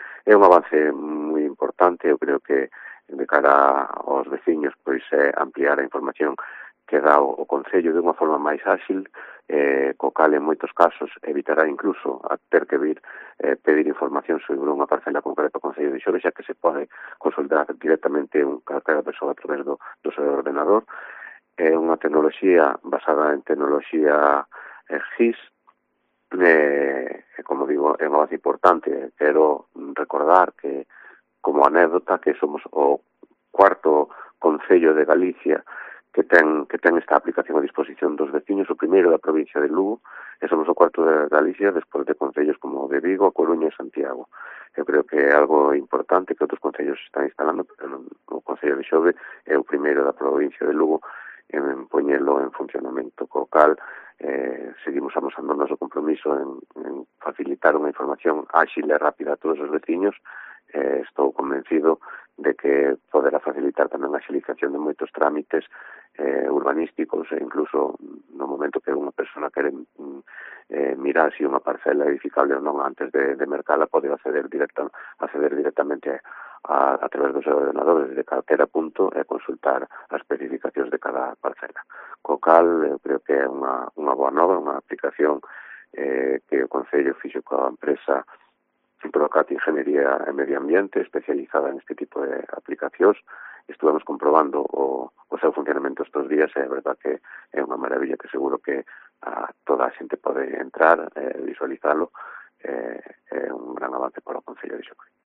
Demetrio Salgueiro, alcalde de Xove, valora el avance que significa este Geoportal